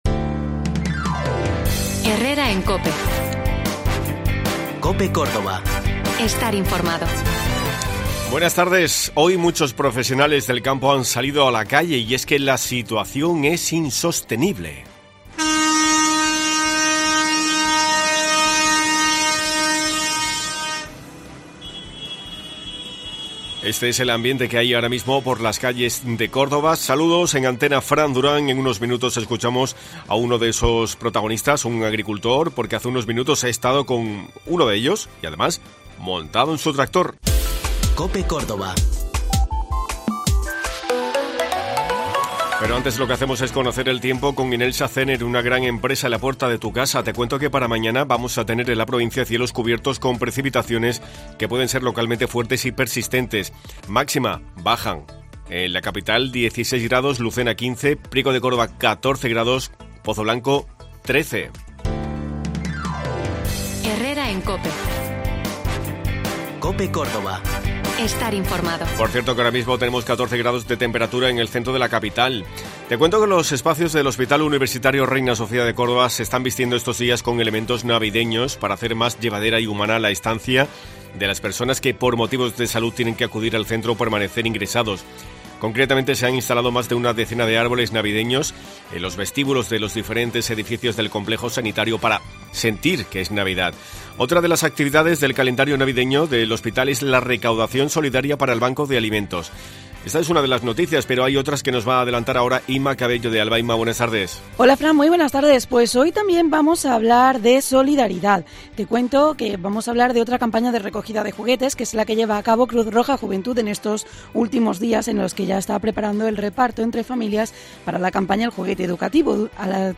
Esta mañana miles de agricultores han participado en la caravana de protestas convocadas por las principales organizaciones agrarias. Hemos estado junto a los profesionales del campo escuchando sus demandas e inquietudes. Además hemos conocido el pronóstico del tiempo en un día donde la lluvia nos ha visitado en el primer día de invierno.